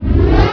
whoosh_pickup.WAV